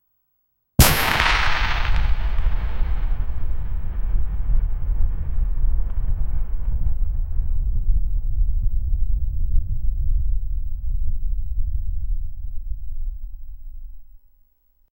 active-artillery-fire-vgllepfs.wav